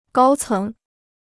高层 (gāo céng) Kostenloses Chinesisch-Wörterbuch